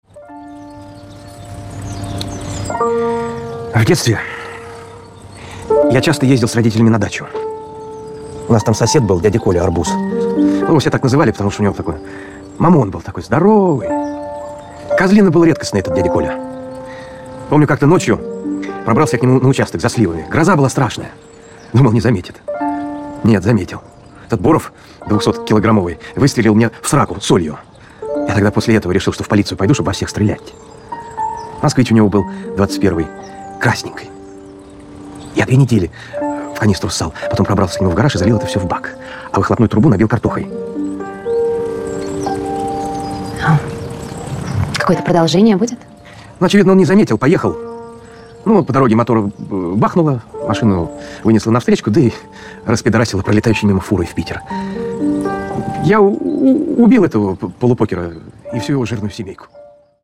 клавишные
пианино
голосовые
цикличные